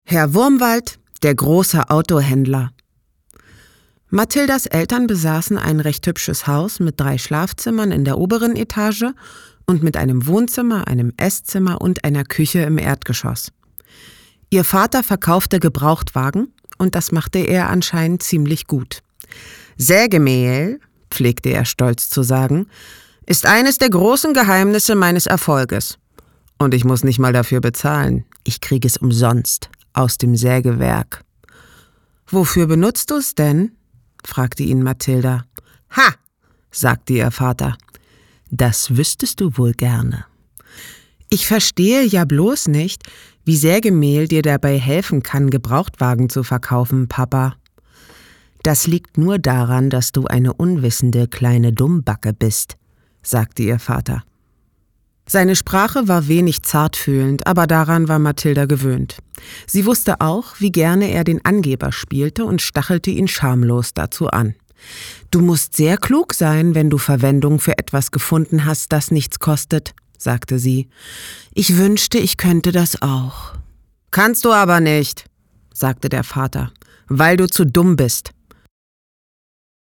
dunkel, sonor, souverän, sehr variabel
Audiobook (Hörbuch)